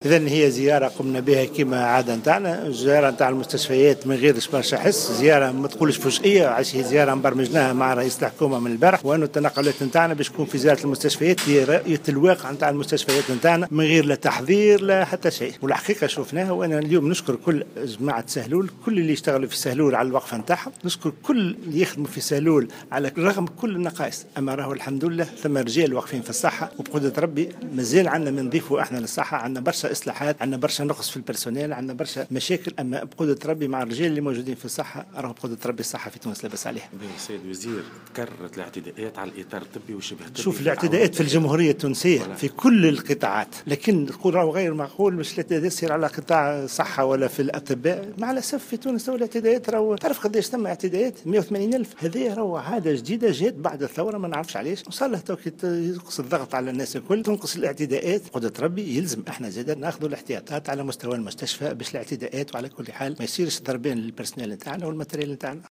أكد وزير الصحة عبد الرؤوف الشريف في تصريح للجوهرة "اف ام" خلال زيارة أداها مع رئيس الحكومة لمستشفى سهلول أن هذه الزيارة تم برمجتها مساء أمس للإطلاع على سير العمل داخل المستشفى لرؤية الواقع دون تحضيرات أو استعدادات.